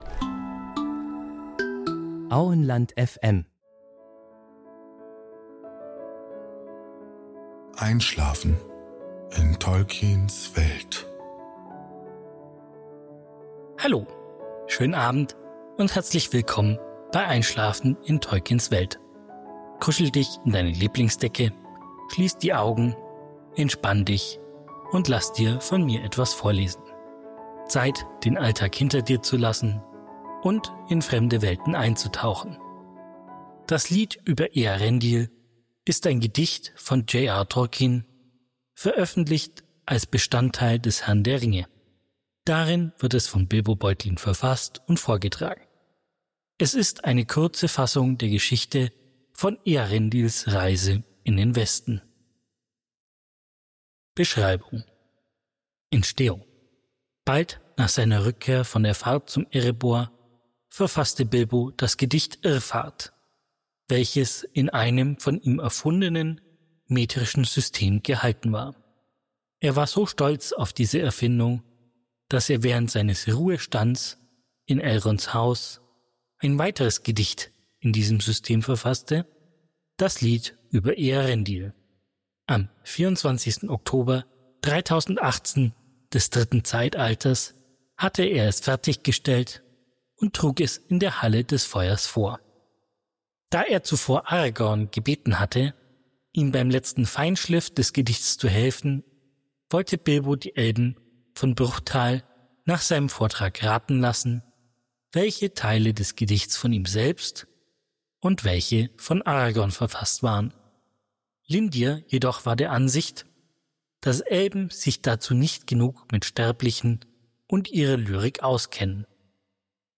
Heute erzählen wir Dir zum Einschlafen etwas aus Tolkiens Welt. Dazu lesen wir Dir ausgewählte Artikel aus der Ardapedia vor.